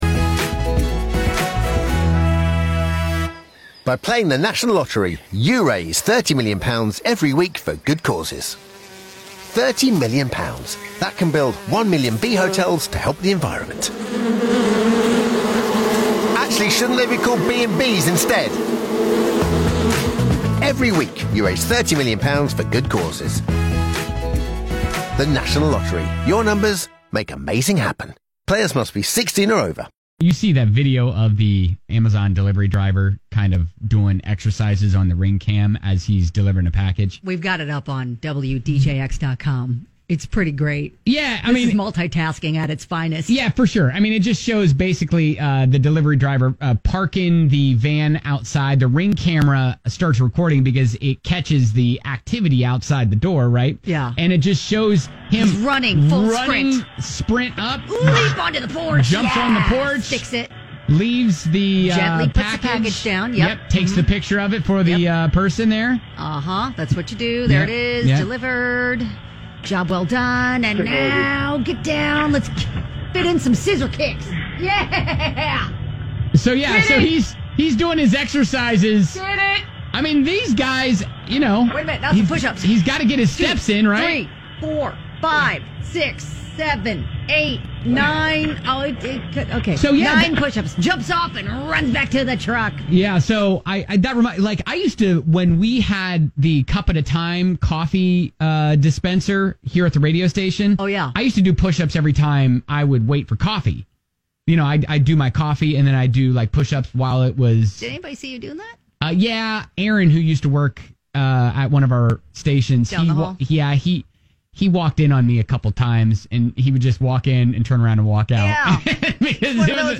Gotta be transparent when we tell you there might have been an accidental them that emerged in this show...made even more evident when listening to the podcast without the buffer of music and commercials.